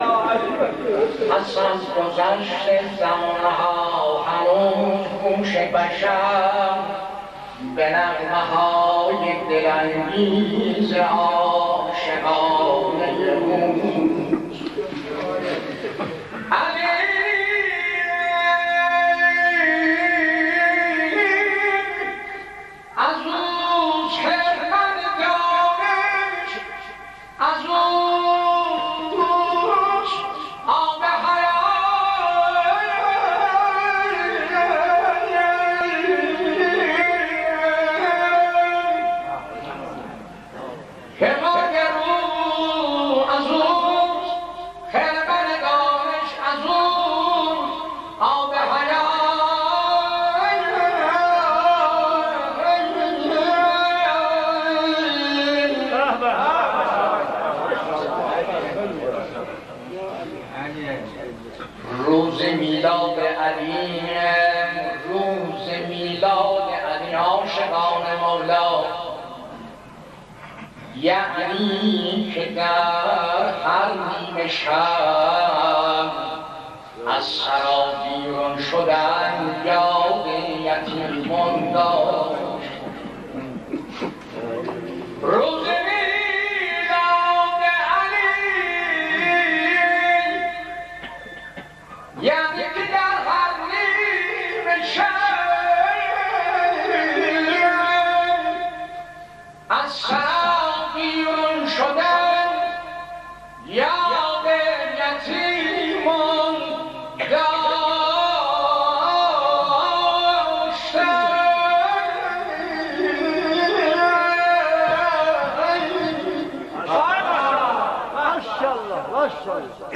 مداحی آذری
مولودی ترکی